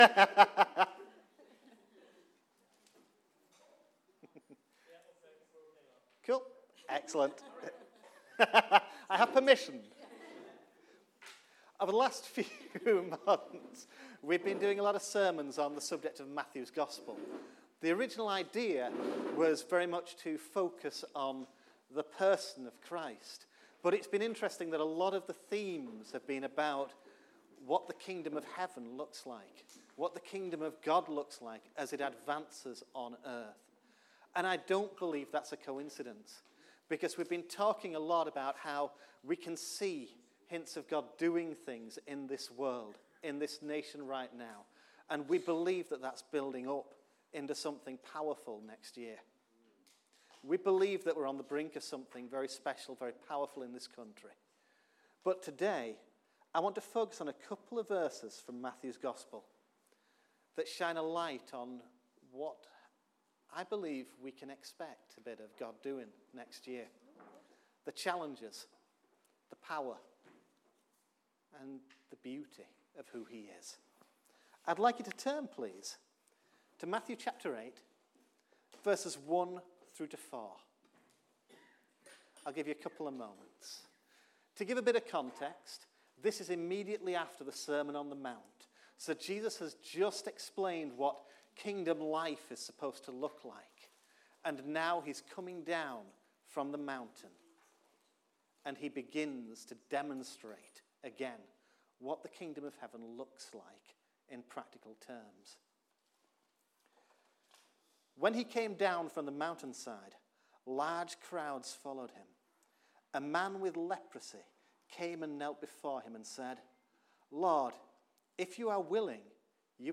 OCF Sermons